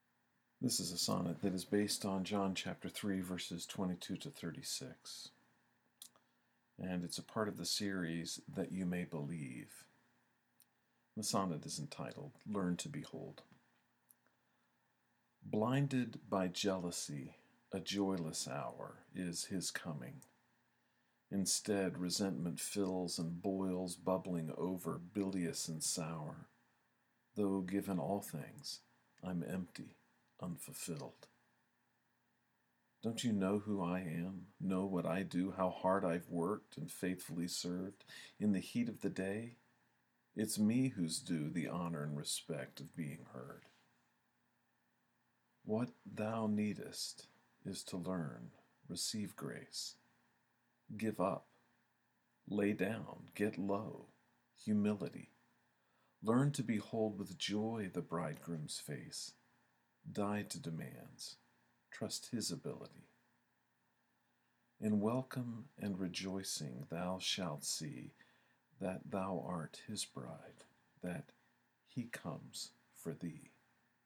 If it’s helpful you may listen to me read the sonnet via the player below.